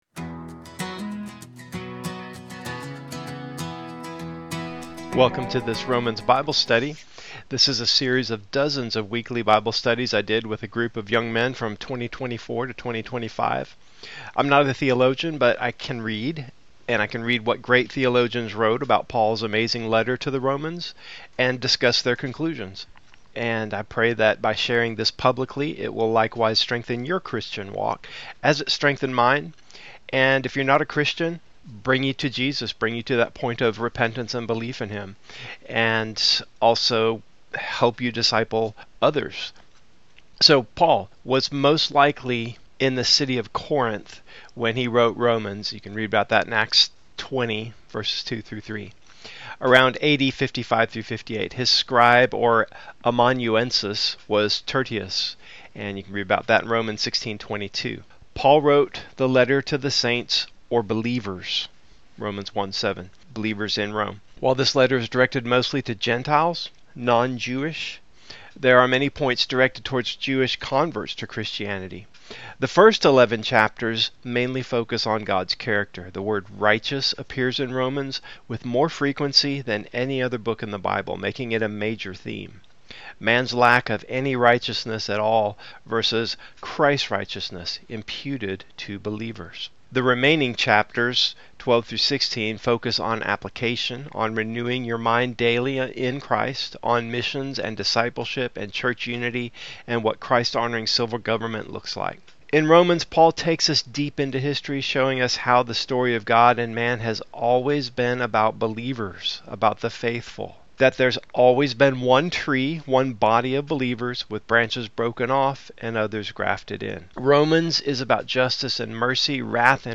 Romans Bible Study-About
This is a series of dozens of weekly Bible studies I did with a group of young men from 2024-2025.